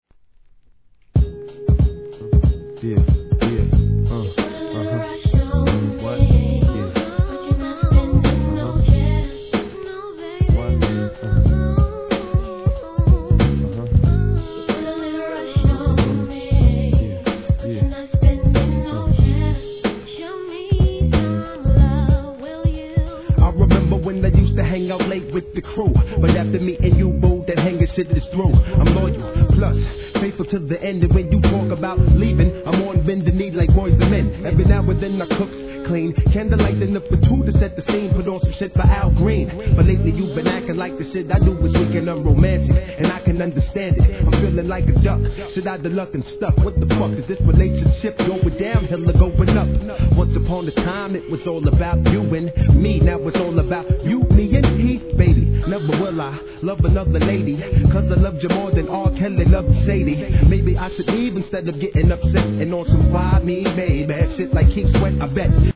HIP HOP/R&B
お洒落なメロ〜トラックに女性コーラス・フックのA面に